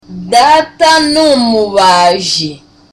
Dialogue: A conversation between Tereza and Sonita
(Smiling)